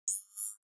دانلود صدای ربات 54 از ساعد نیوز با لینک مستقیم و کیفیت بالا
جلوه های صوتی